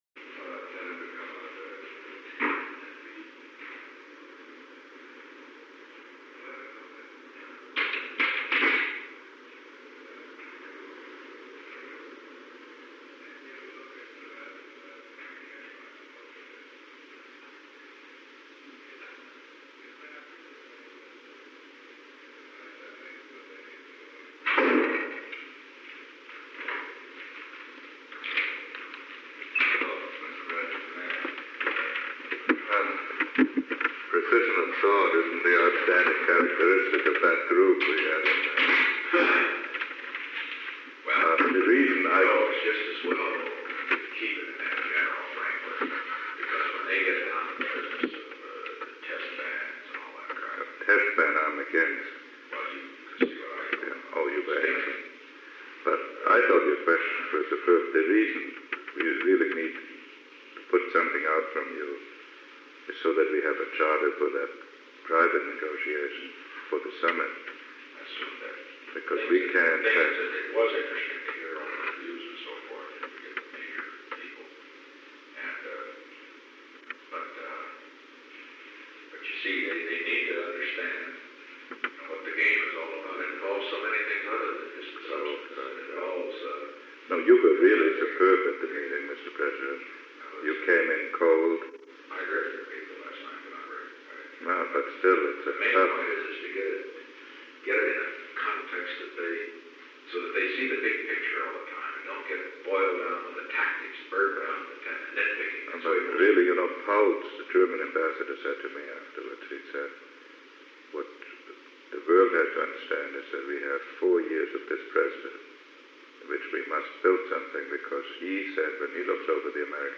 Secret White House Tapes
Location: Oval Office
The President met with Henry A. Kissinger.
An unknown man entered at an unknown time after 11;55 am.